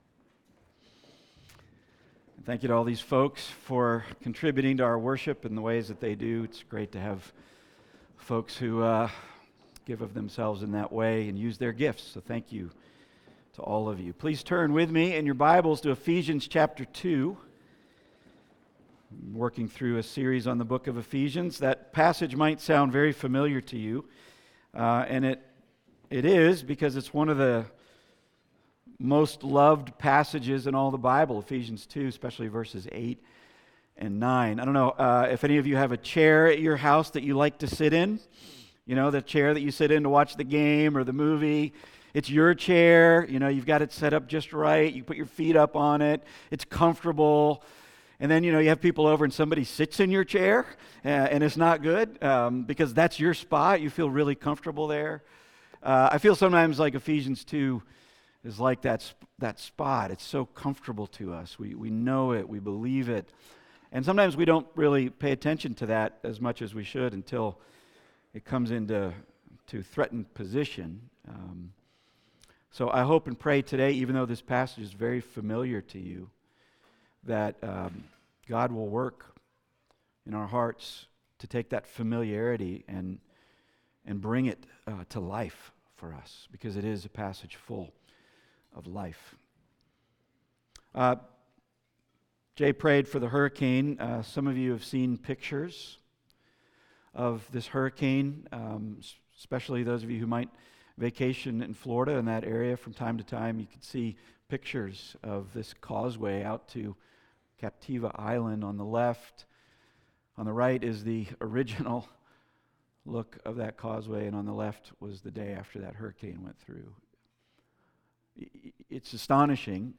Passage: Ephesians 2:1-10 Service Type: Weekly Sunday